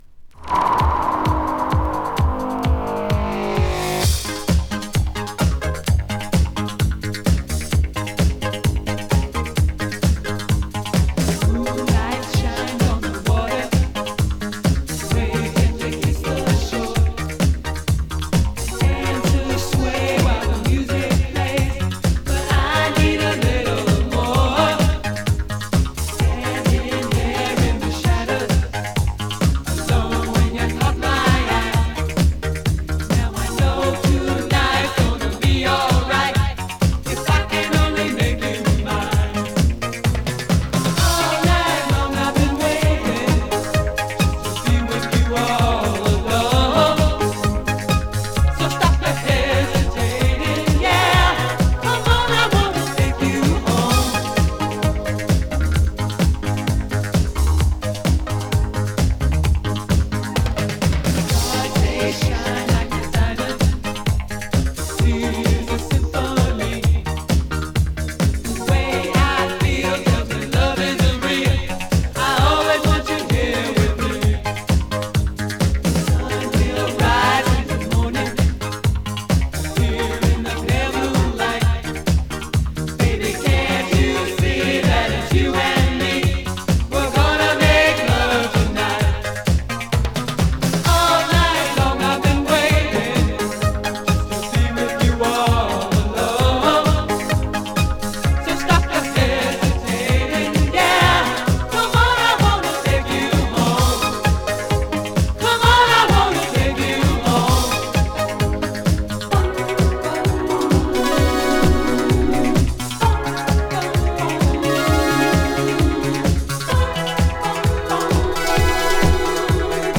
【HI-NRG】【DISCO】